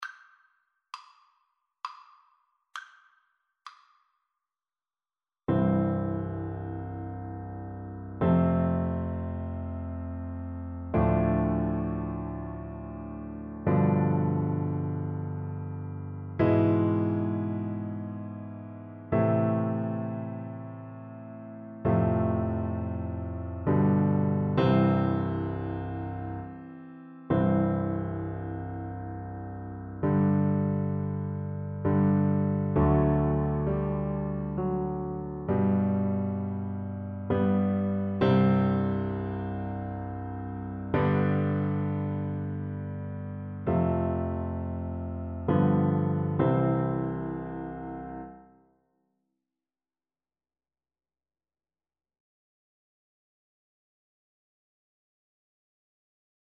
Oboe
"We Wish You a Merry Christmas" is a popular sixteenth-century English carol from the West Country of England.
C major (Sounding Pitch) (View more C major Music for Oboe )
Moderato
3/4 (View more 3/4 Music)